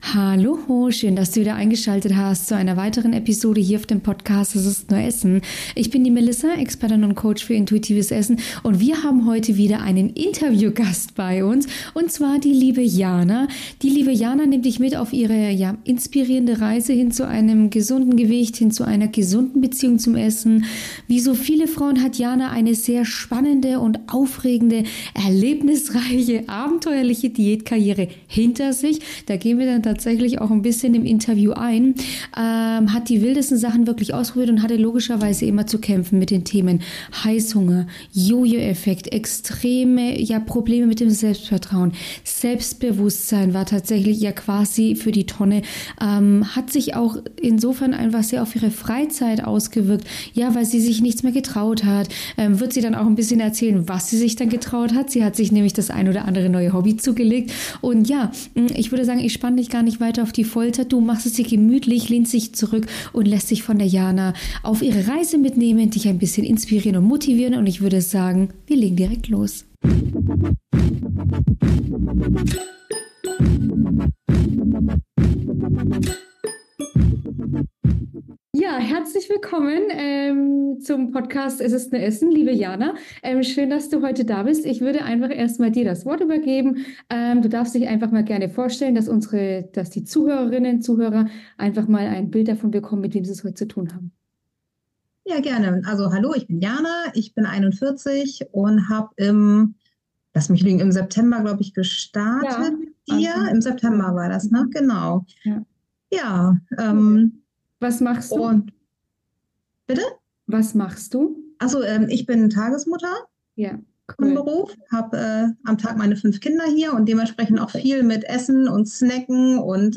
Erfolgsinterview Go for eat